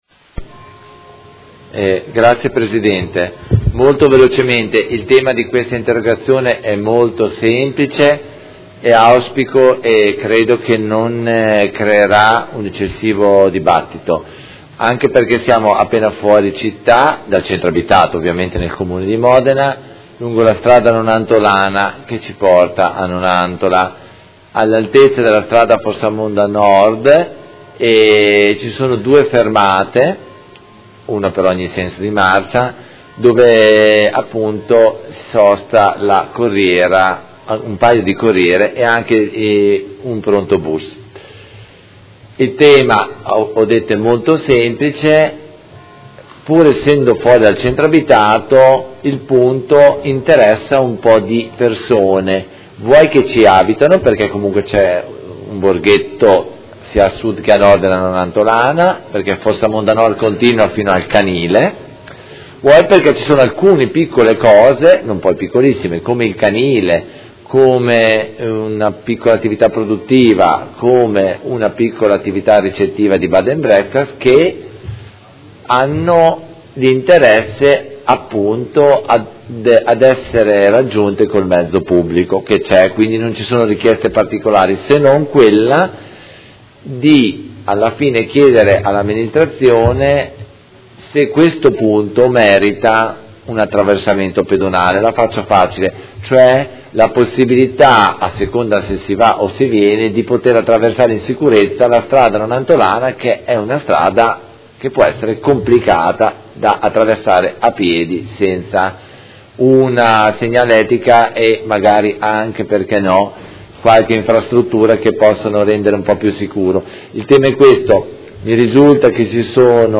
Seduta del 23/03/2017 Interrogazione del Consigliere Carpentieri (PD) avente per oggetto: Sicurezza su strada Nonantolana all’incrocio con strada Fossamonda Nord